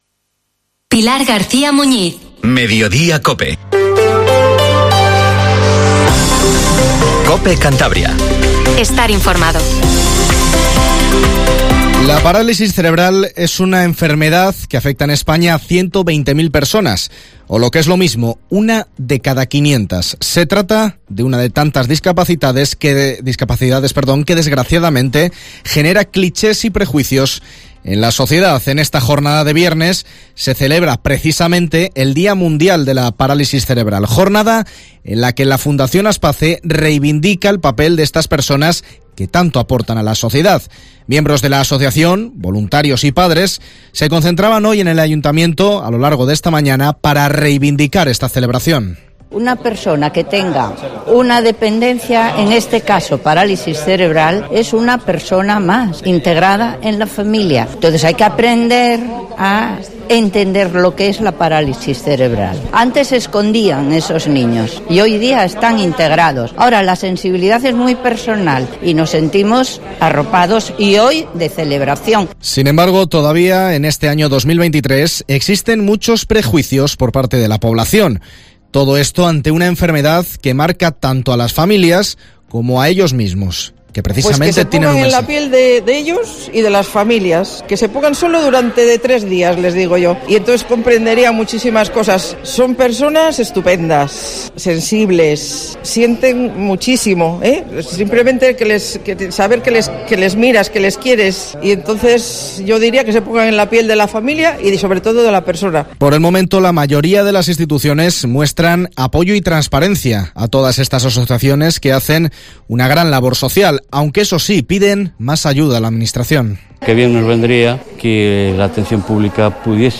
Informativo MEDIODIA en COPE CANTABRIA 14:48